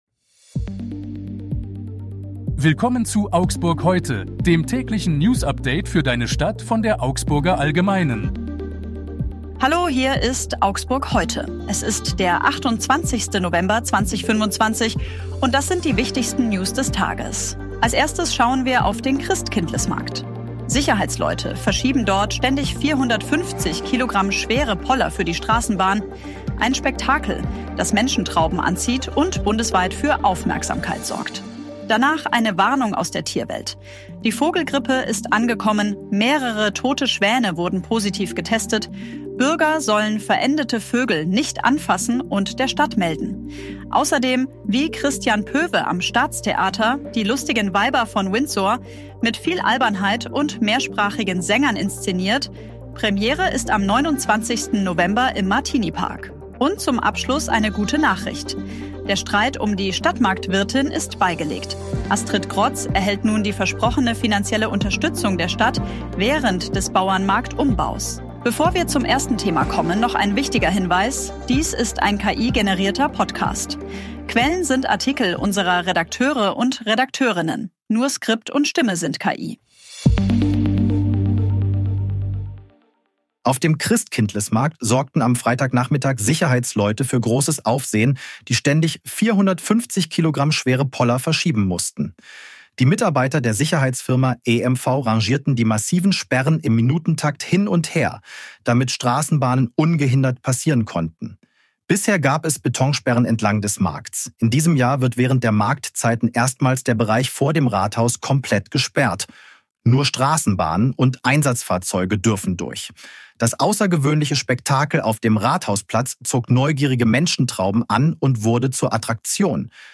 Skript und Stimme sind KI.